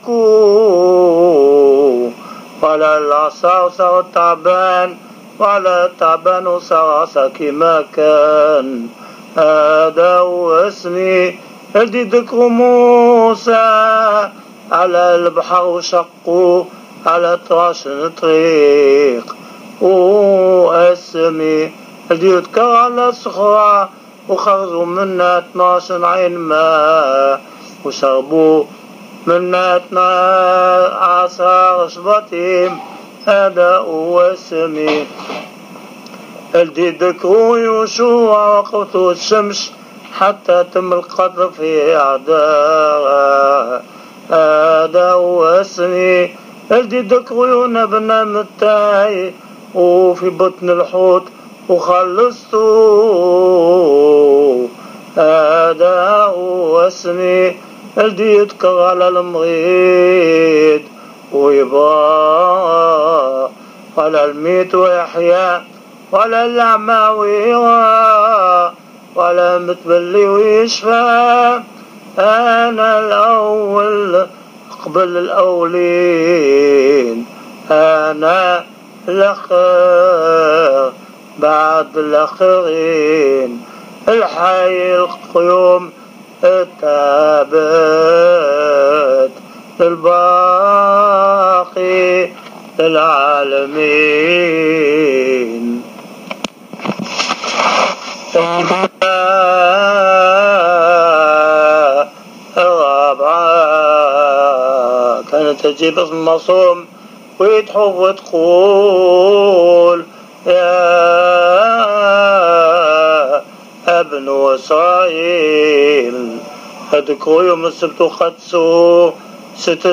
Les lectures des Dix Commandements